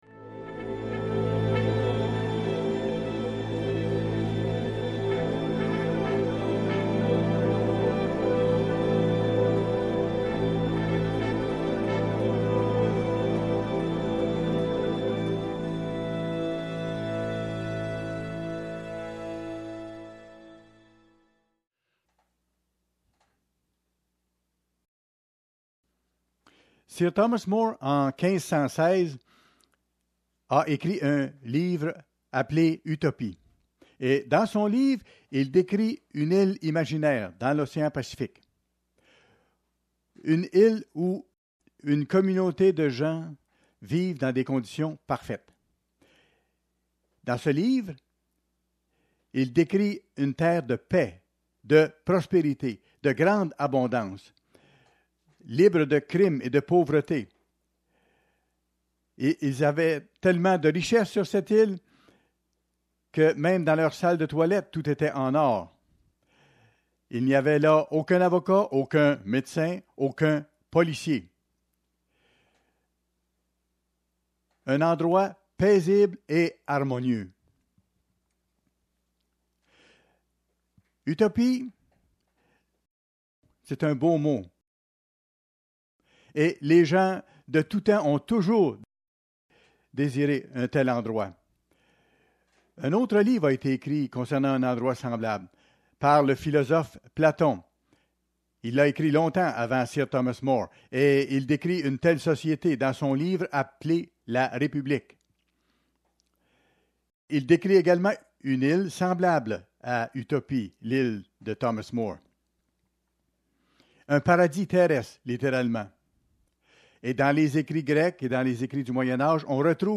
Fête des Tabernacles, 3ème jour Utopie ?